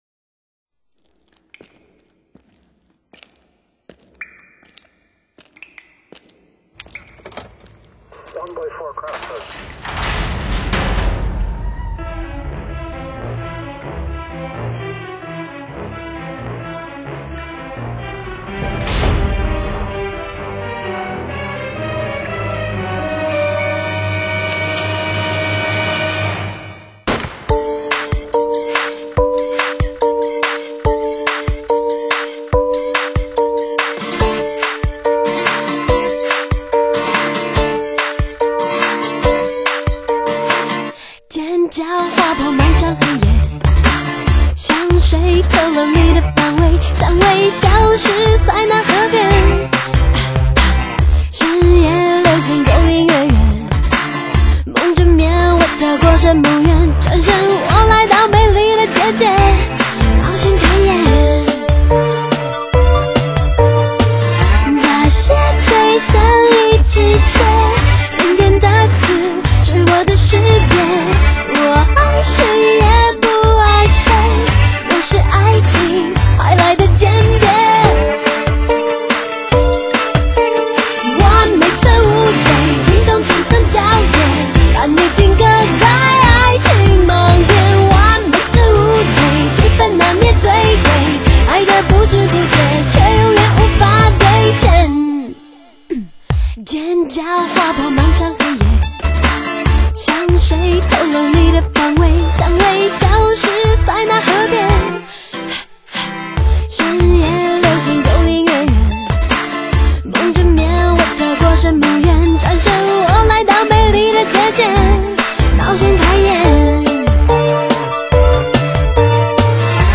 戏剧性的配乐，加上强劲的节奏